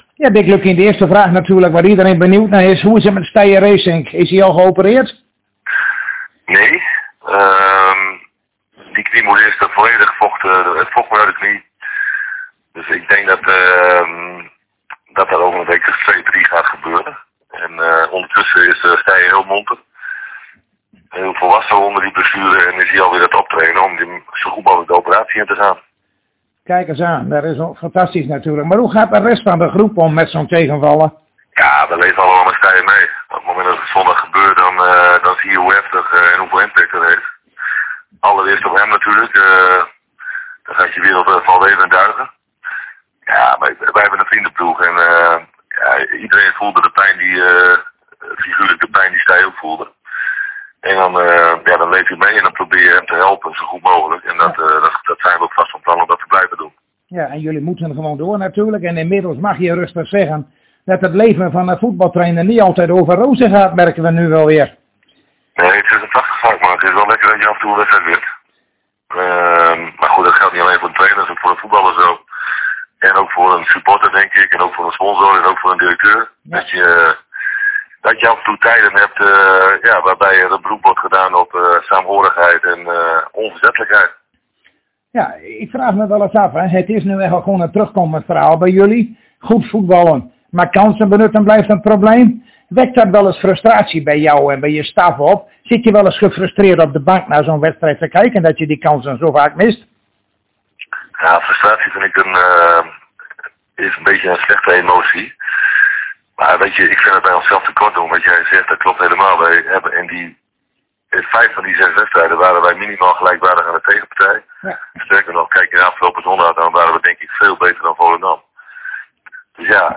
Zojuist spraken wij weer met Dick Lukkien en uiteraard hadden we het even over Stije Resink maar vooral over de wedstrijd die FC Groningen morgen speelt tegen Ajax.